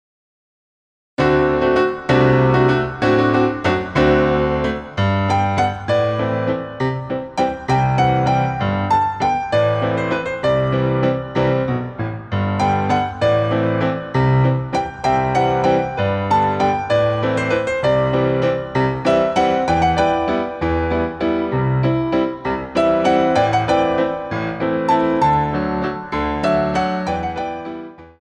MEDIUM ALLEGRO I